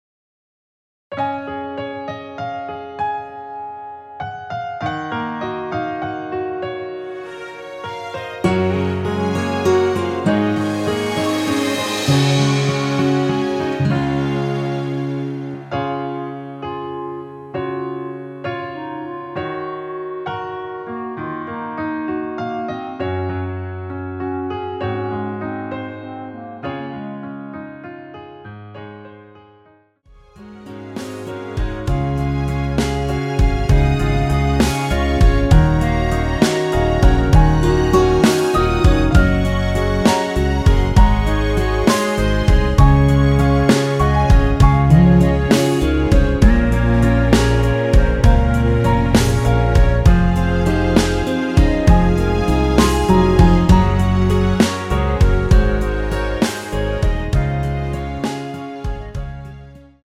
원키에서(-7)내린 멜로디 포함된 MR 입니다.(미리듣기 참조)
Db
멜로디 MR이라고 합니다.
앞부분30초, 뒷부분30초씩 편집해서 올려 드리고 있습니다.
중간에 음이 끈어지고 다시 나오는 이유는